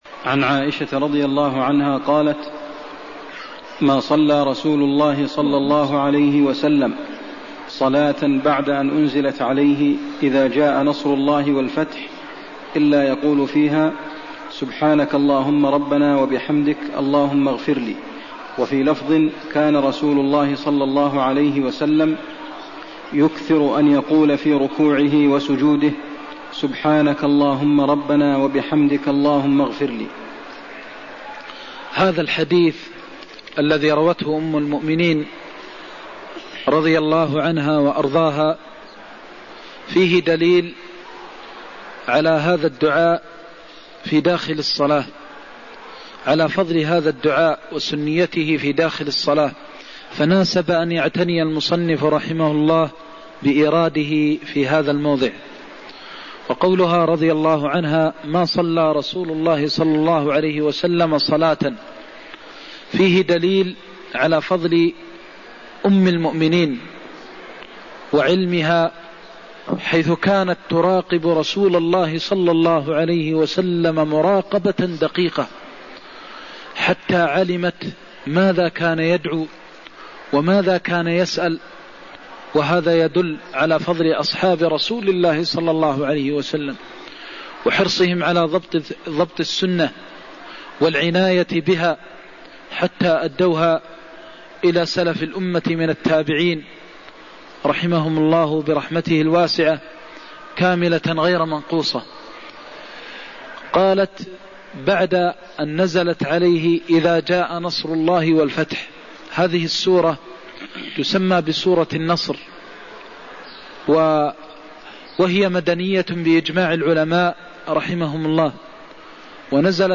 المكان: المسجد النبوي الشيخ: فضيلة الشيخ د. محمد بن محمد المختار فضيلة الشيخ د. محمد بن محمد المختار الدعاء والتسبيح في الركوع والسجود (118)